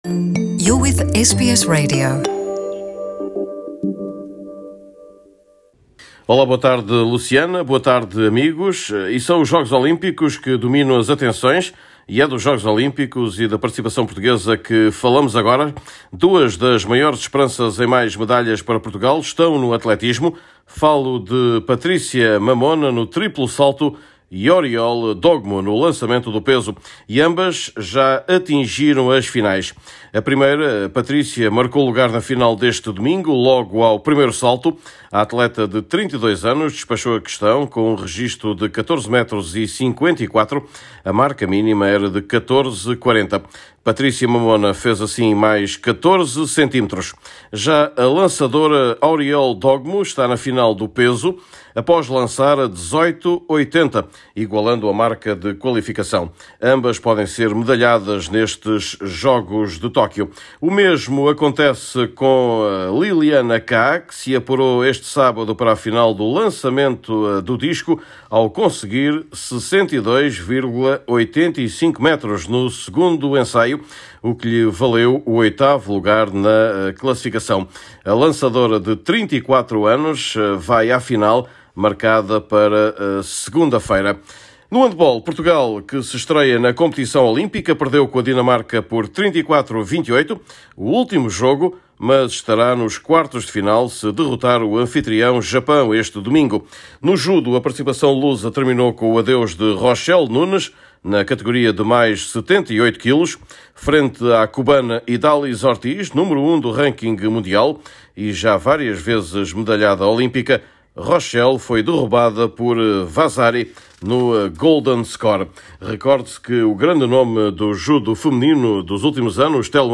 Neste boletim esportivo semanal, destaque para o futebol luso e para o Benfica à procura de estabilidade com o novo presidente, Rui Costa.